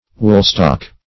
woolstock - definition of woolstock - synonyms, pronunciation, spelling from Free Dictionary
Woolstock \Wool"stock`\, n.